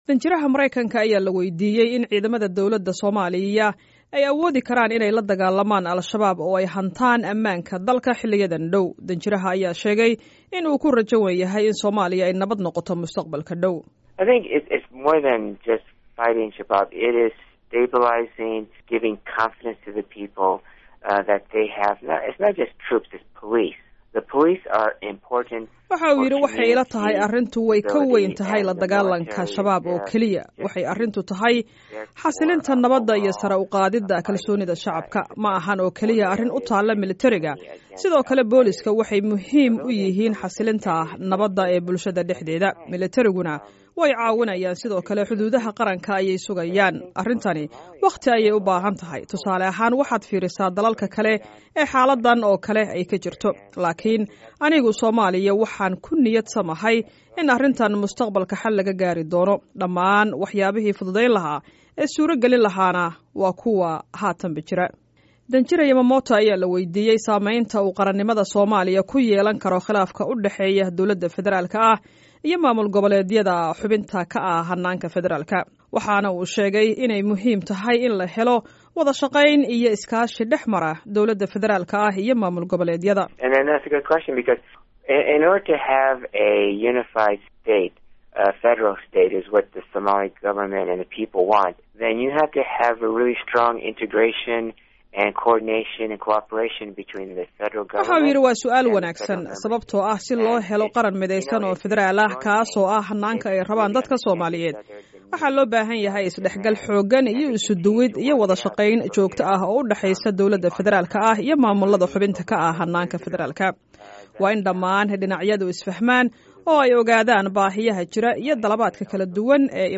Wareysi: Safiirka Mareykanka ee Soomaaliya
Safiirka Mareykanka u joogo Somalia, Donald Yamamoto, ayaa Wareysi gaar ah oo uu siiyey Laanta Afka Somaliga ee VOA uga hadlay arrimo badan.